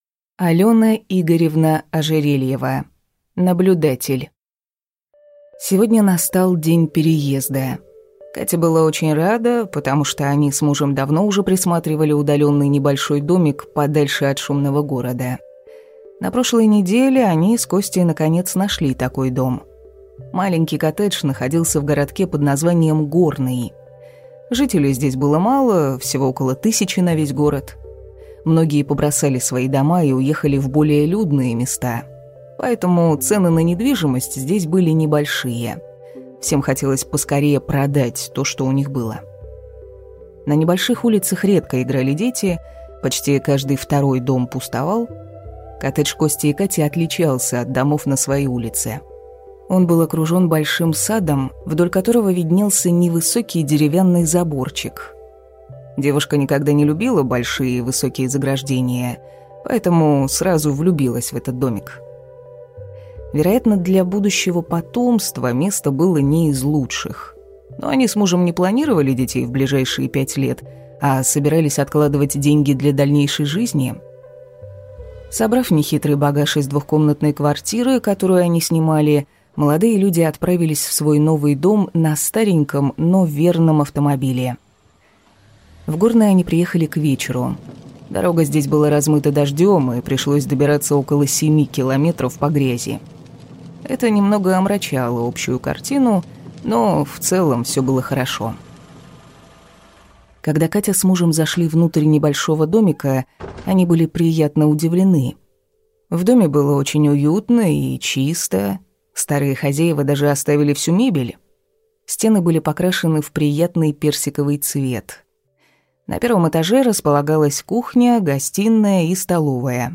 Аудиокнига Наблюдатель | Библиотека аудиокниг